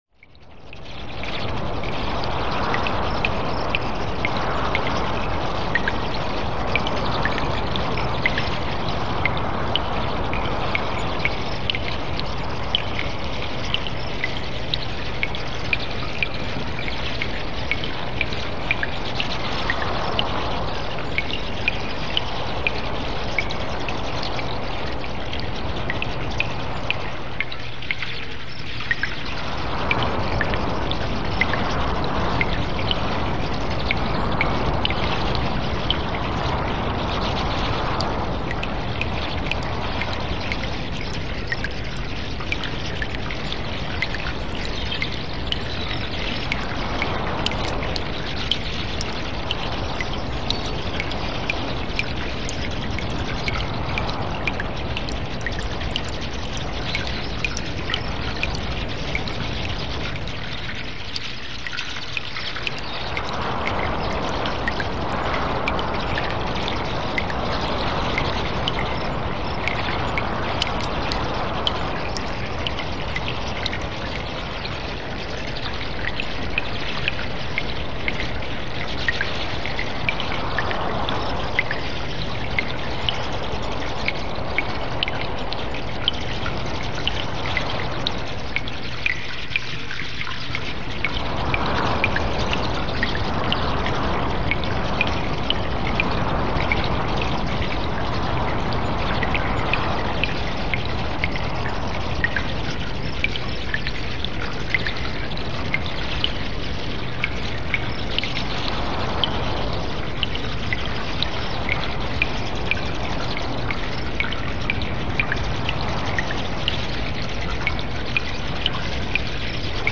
cave_background.mp3